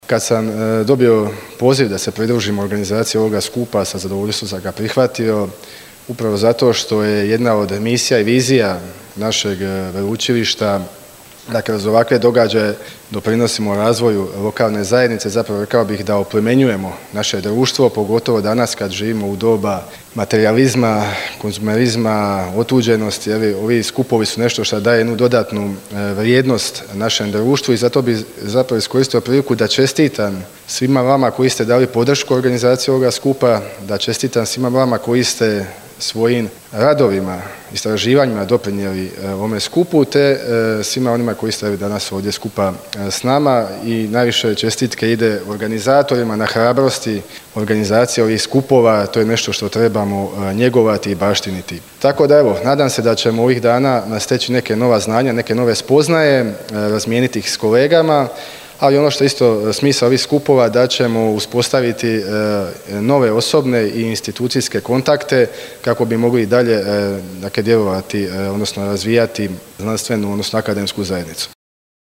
Znanstveni skup o Siveriću – pogled u povijest s ciljem jasnije budućnosti